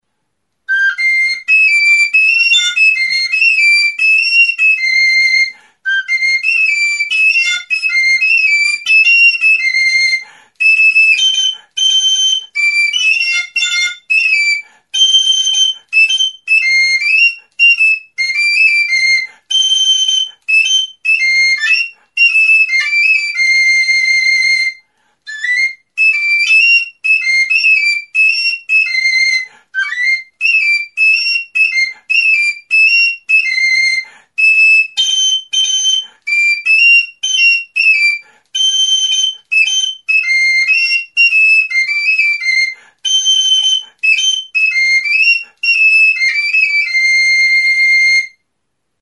Aerophones -> Flutes -> Fipple flutes (one-handed)
Recorded with this music instrument.
Hiru zuloko flauta zuzena da, ebanozko bi zatitan egindako txistu txikia (Do tonuan), eraztun eta ahoko metalikoekin.